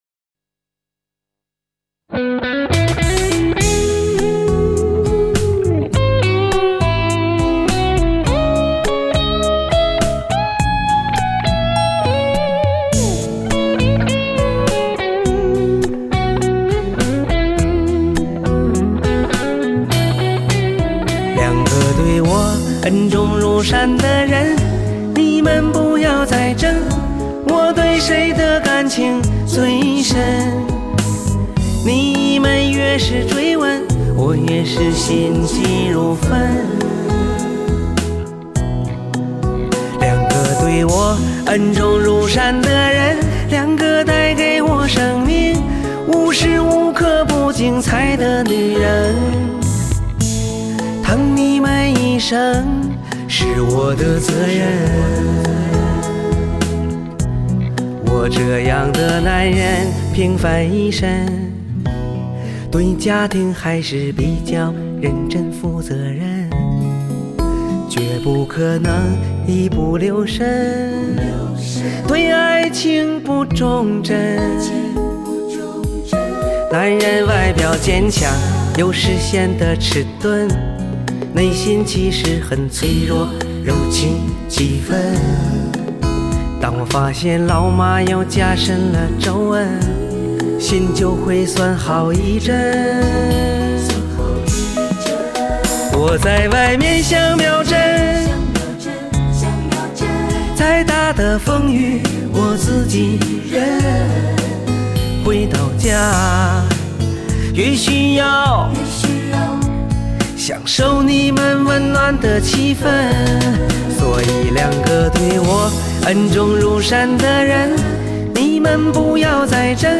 幽默风趣的演出，让观众捧腹大笑，掌声不断。